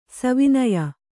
♪ savinaya